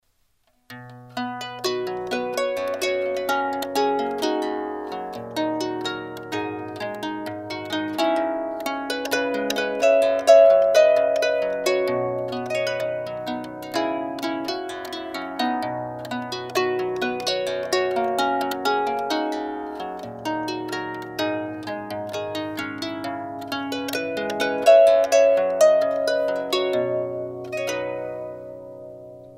wedding musicThe Nearness of You           R & B Favorites